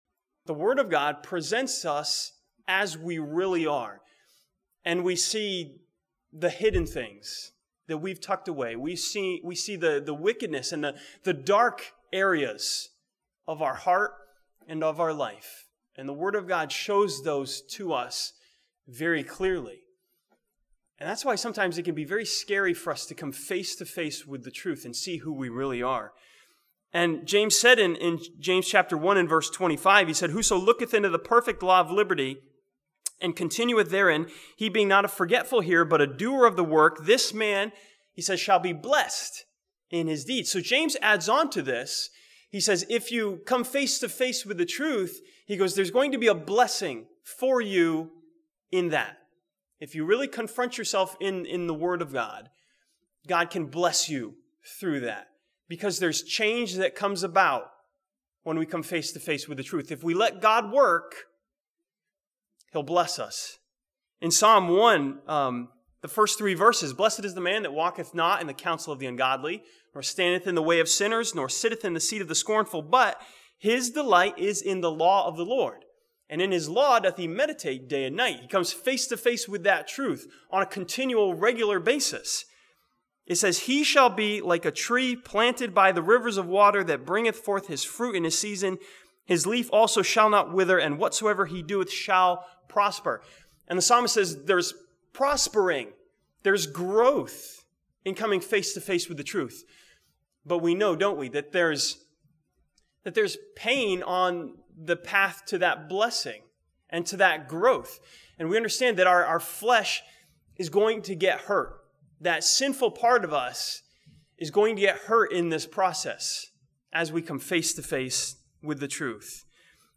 This sermon from John chapter 18 looks at the trial of Christ and studies the responses of those who were facing the truth.